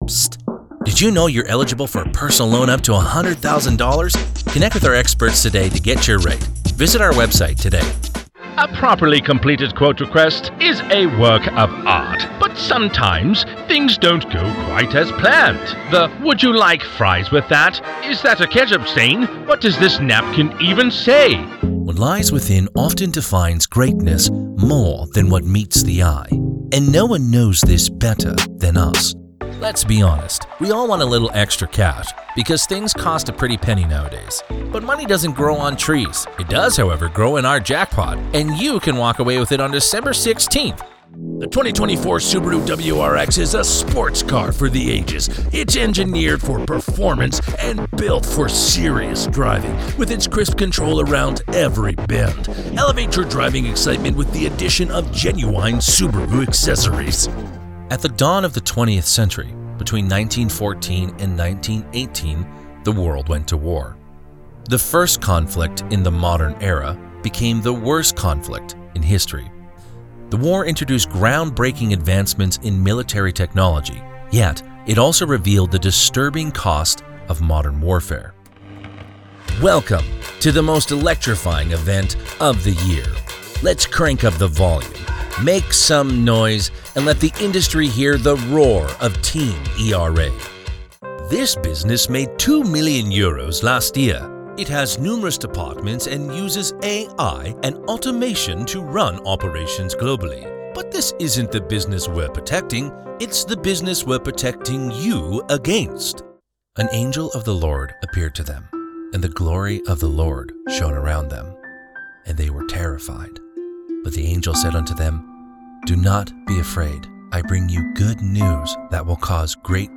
My voice is highly versatile, capable of sounding youthful and energetic, mature and wise, or aged and weathered.
Corporate
Commercial Voice Over Male
0120Commercial_Voice_Over_Demo.mp3